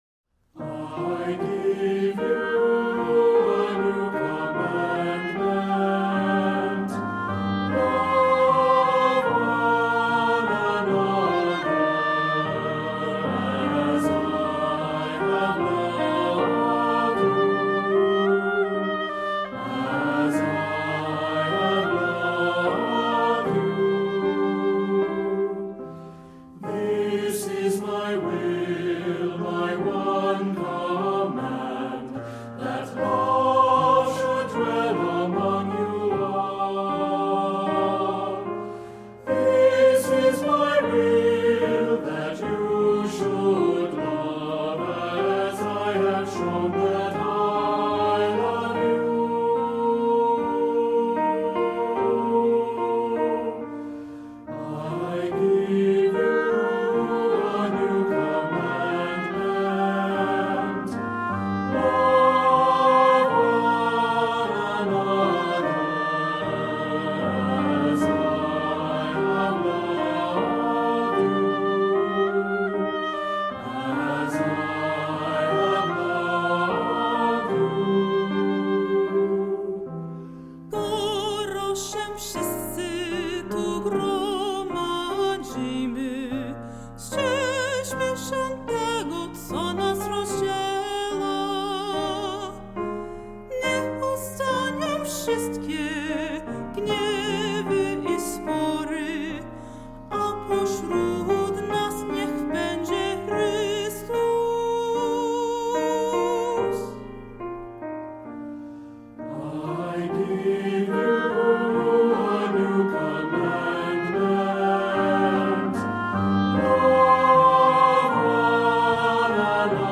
Voicing: Unison choir; descant; assembly